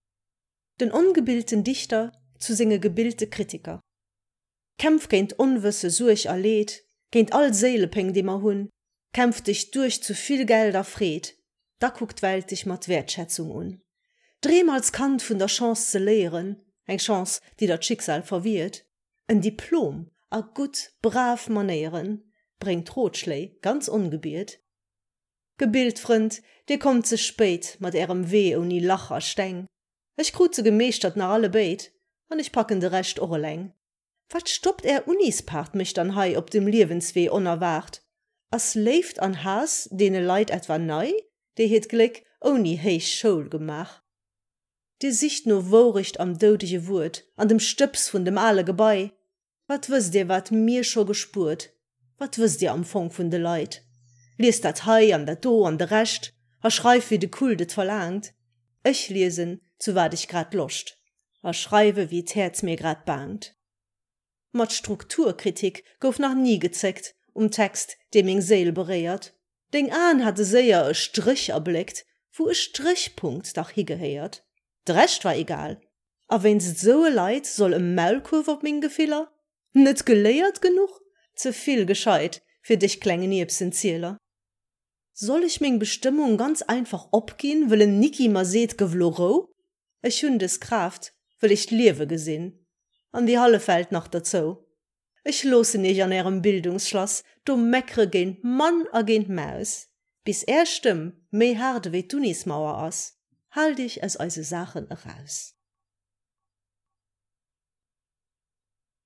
geschwate Versioun.